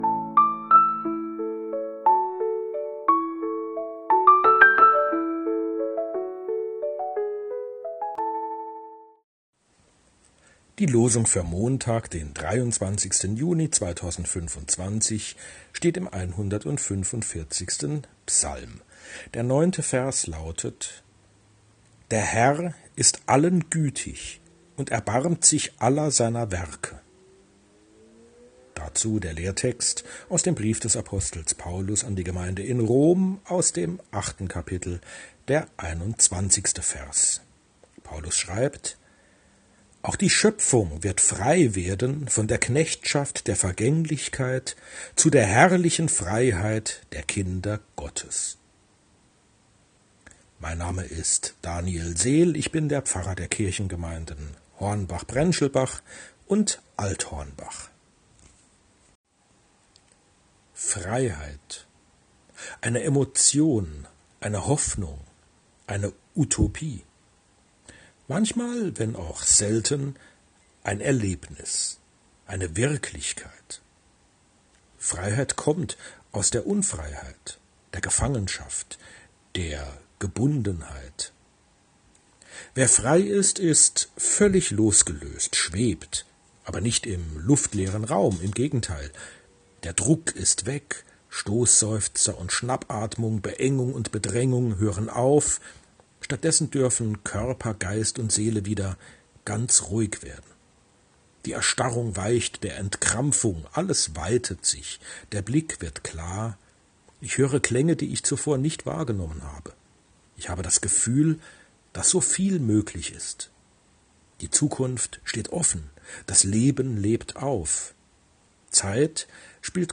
Losungsandacht für Montag, 23.06.2025